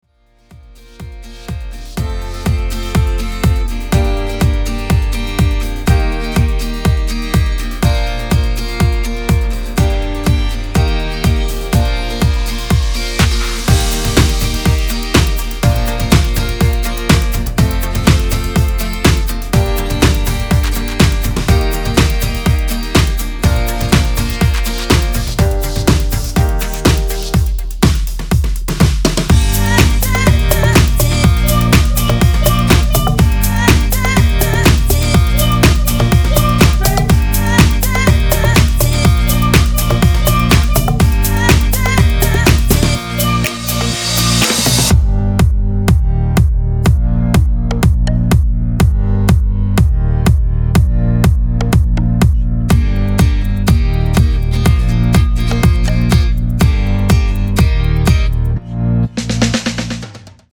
Demo in Eb